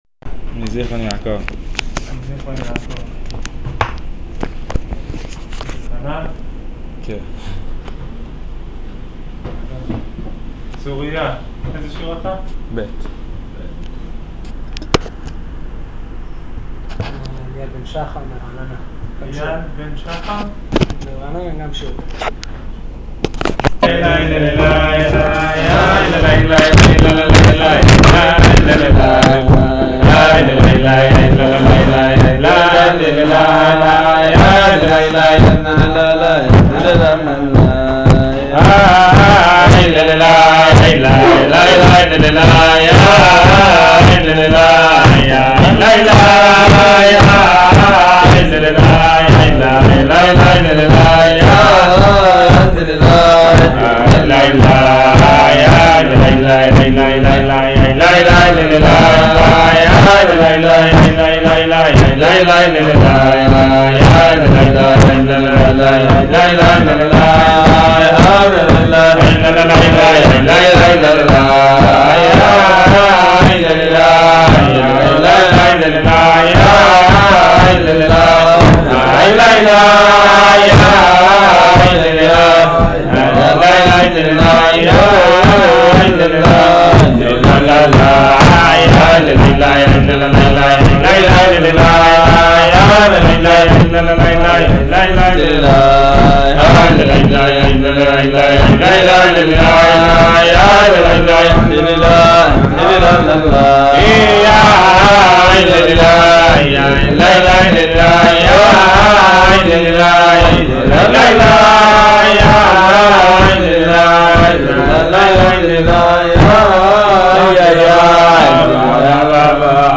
שיעור אגרת התשובה